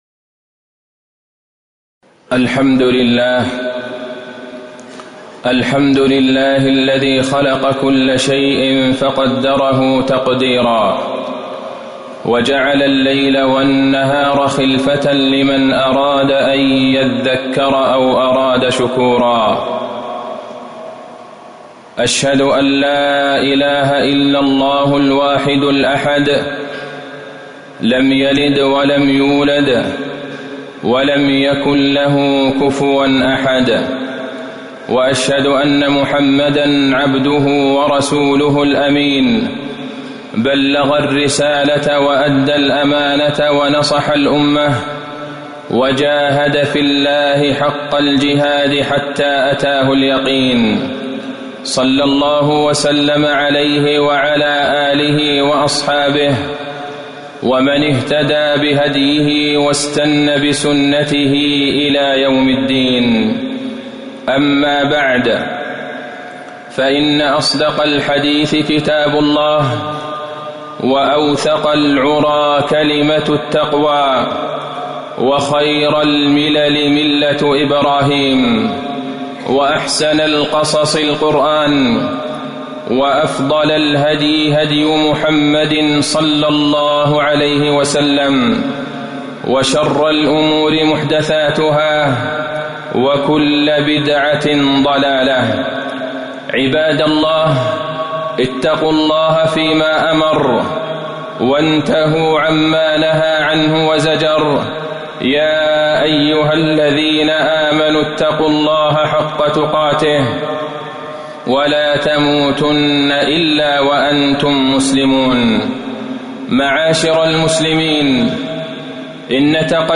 تاريخ النشر ٧ محرم ١٤٤١ هـ المكان: المسجد النبوي الشيخ: فضيلة الشيخ د. عبدالله بن عبدالرحمن البعيجان فضيلة الشيخ د. عبدالله بن عبدالرحمن البعيجان الحث على اغتنام الأوقات